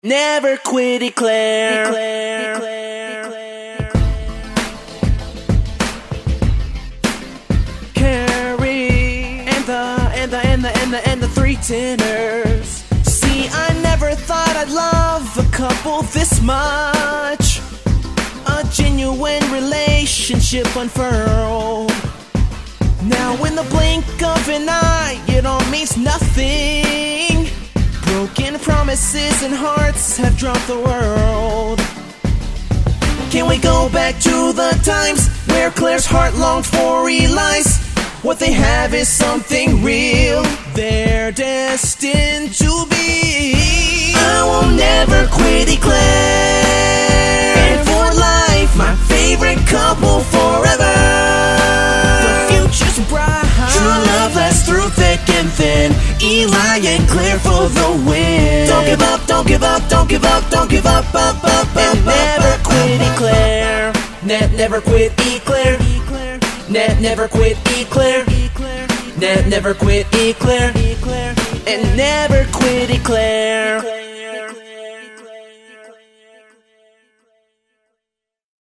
Recorded at Degrassi CS.